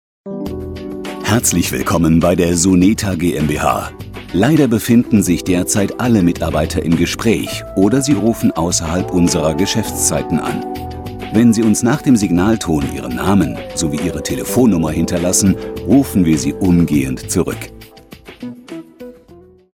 Anrufbeantworter besprechen